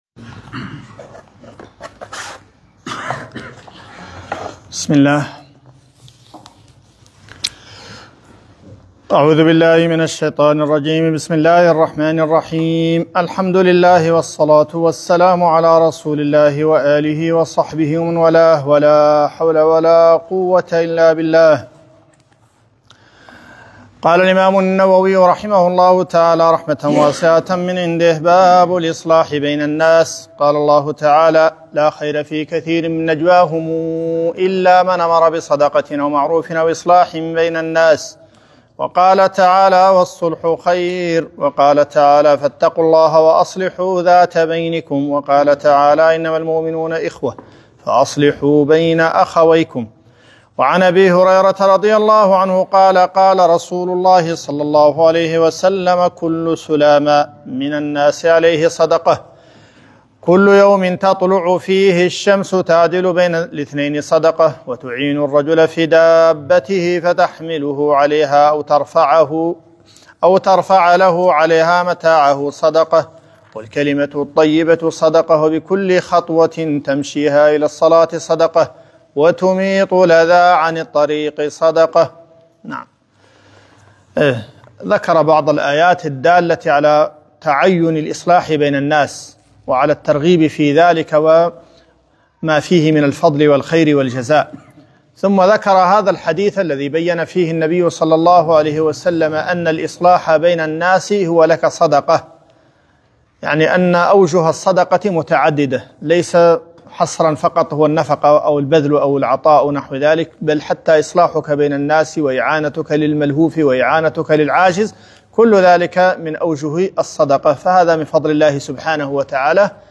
رياض الصالحين الدرس 19